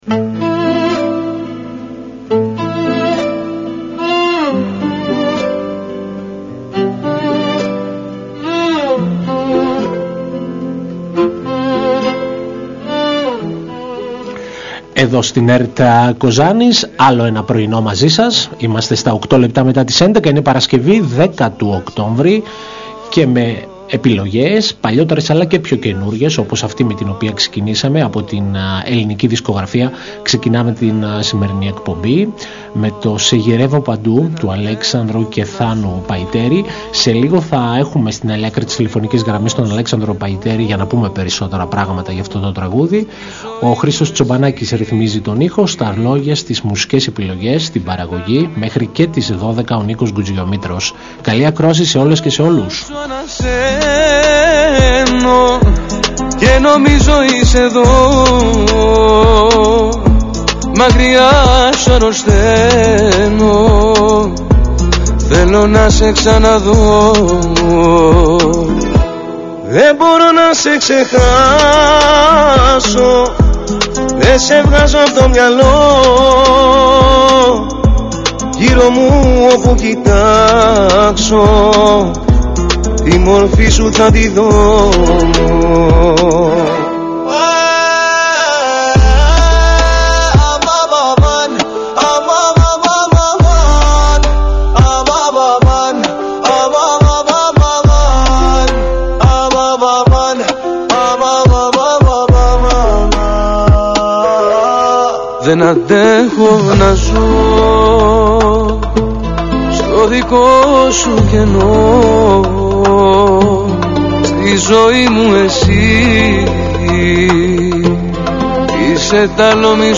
Μια εκπομπή μουσικής και λόγου διανθισμένη με επιλογές από την ελληνική δισκογραφία.